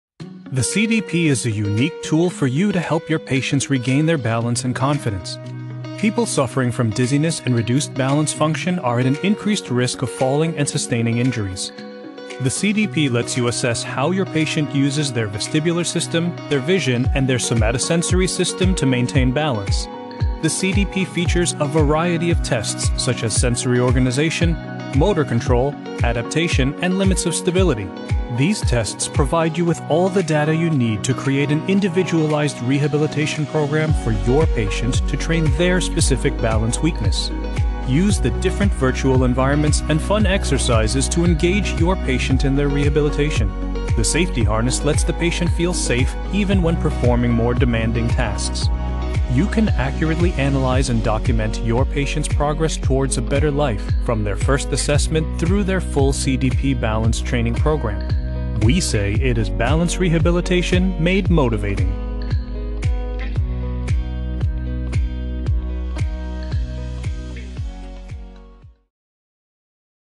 Male
Medical Narrations
Product Explainer